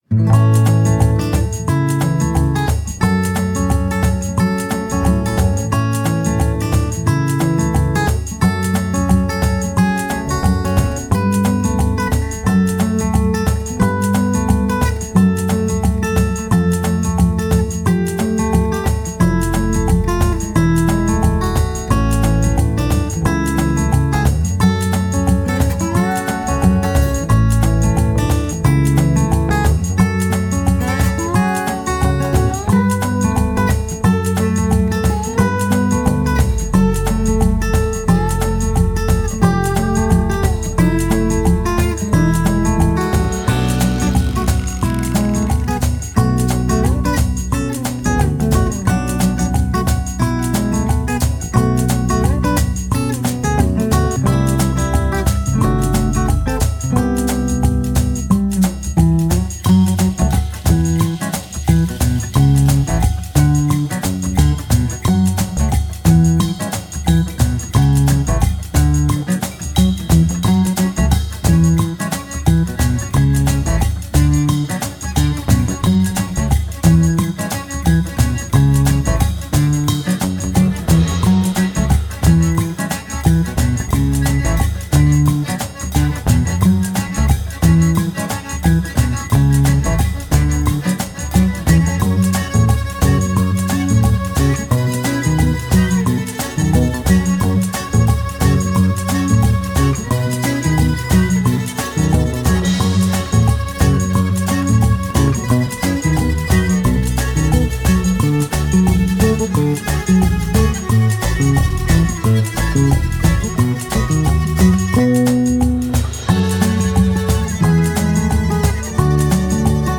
percussão.